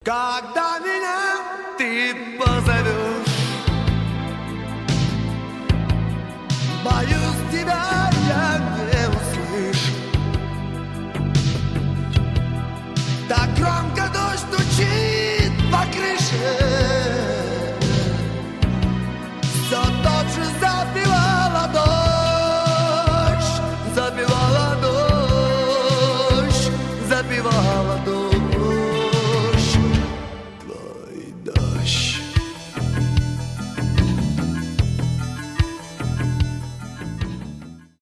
pop rock
ретро